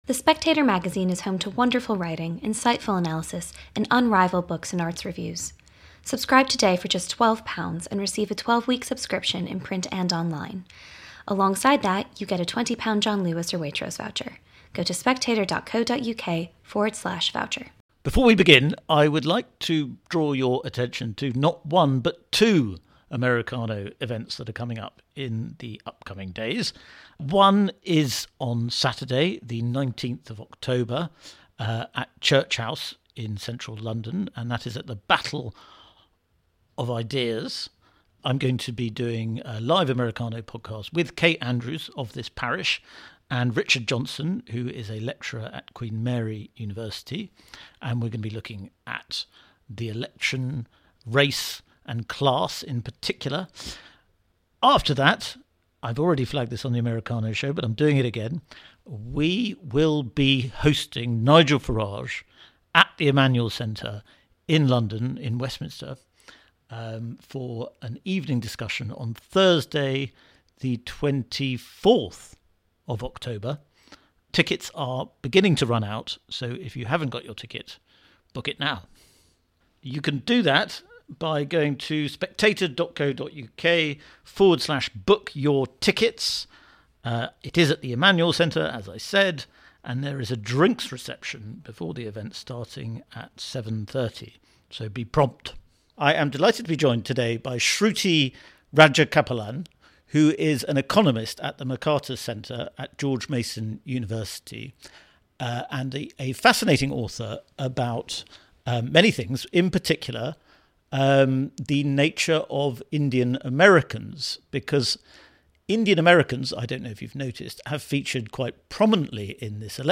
News Talk